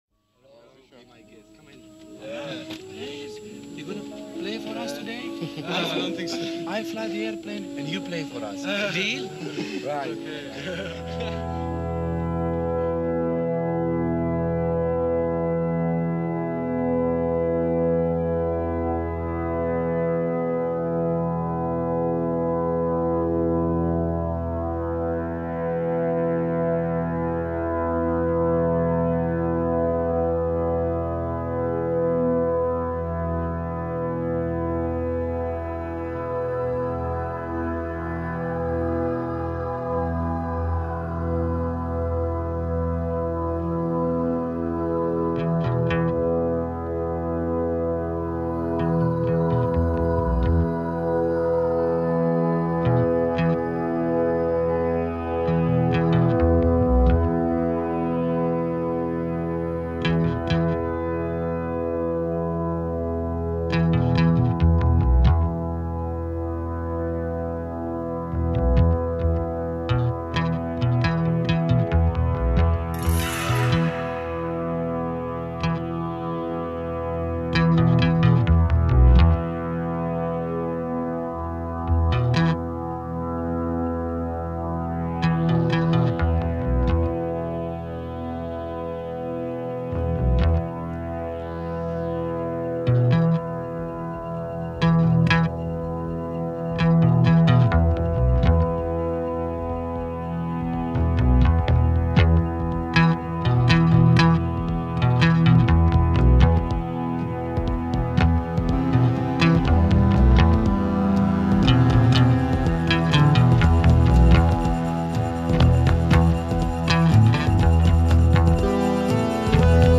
Krautrock, Psychedelic Rock